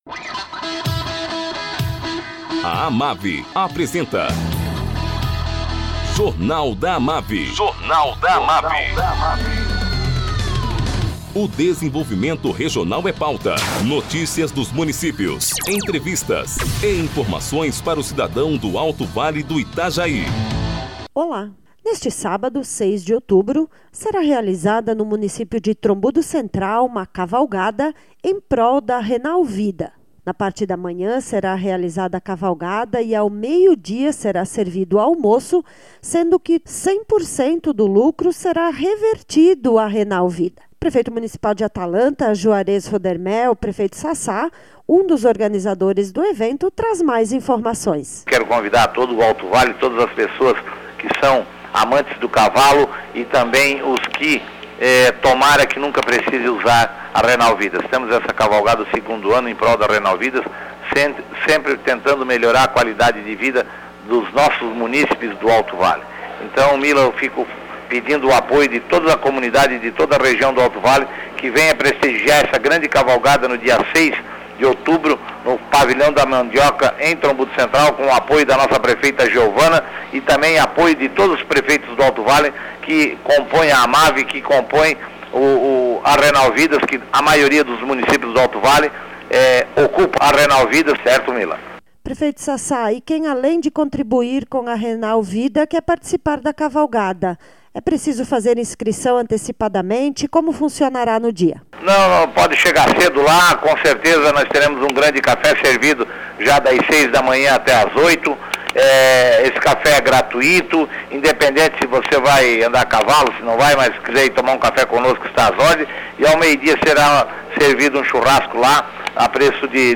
Prefeito de Atalanta, Juarez Rodermel, o Sassá, traz informações sobre a cavalgada que será realizada neste sábado, 06, em Trombudo Central, em prol da Renal Vida.